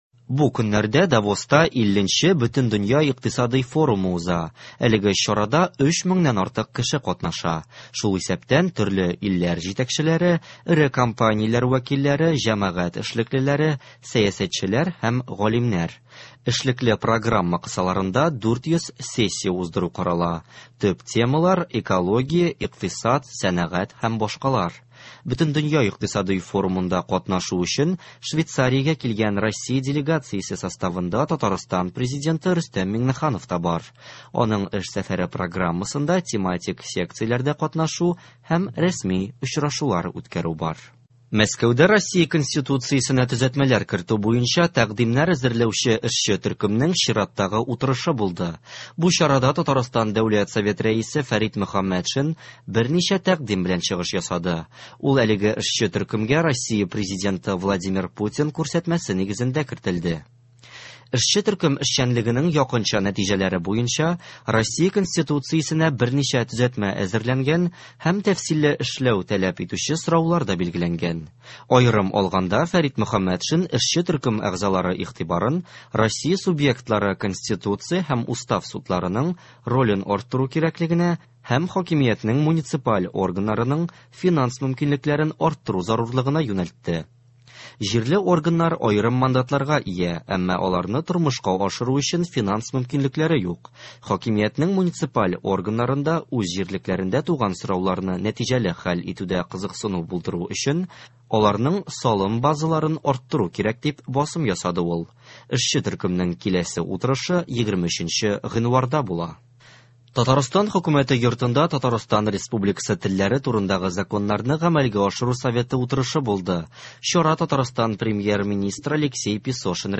Яңалыклар. 22 гыйнвар.